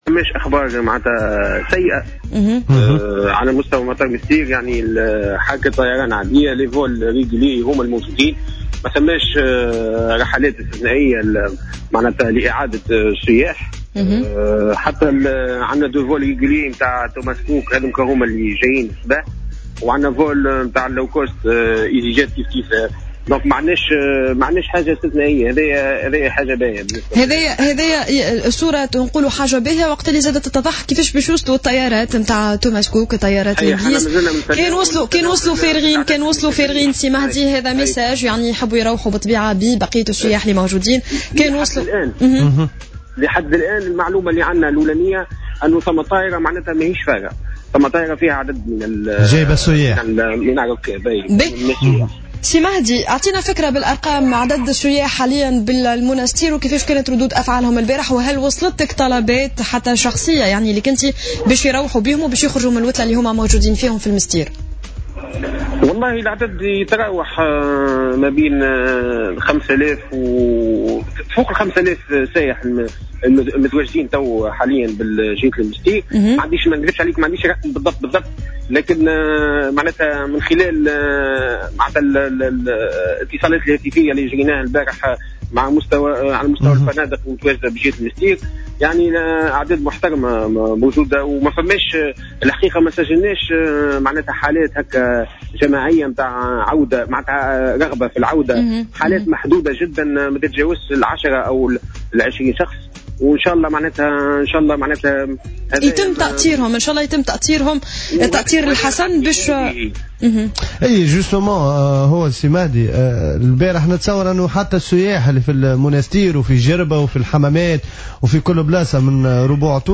قال المندوب الجهوي للسياحة بالمنستير،مهدي الحلوي في تصريح ل"جوهرة أف أم" إن عدد السياح المتواجدين حاليا في جهة المنستير يفوق 5 آلاف سائح.